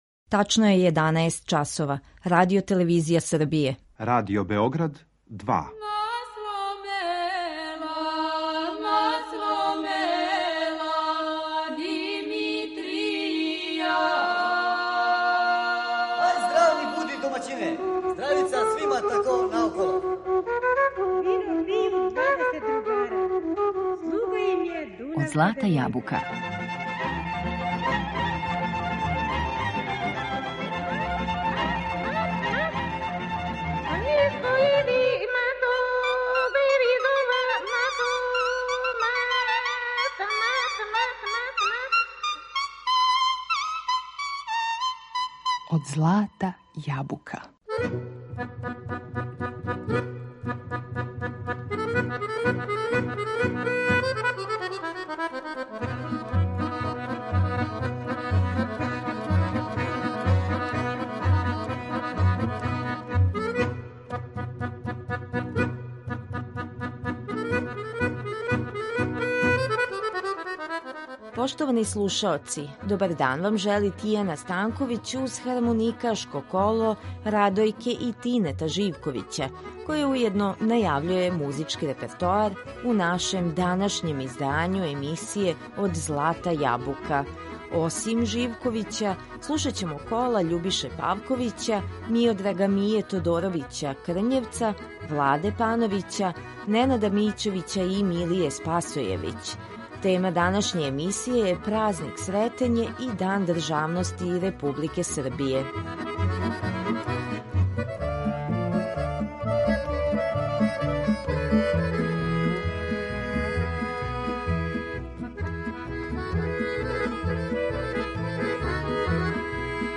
На репертоару су снимци хармоникашких кола у извођењу наших признатих виртуоза на том инструменту.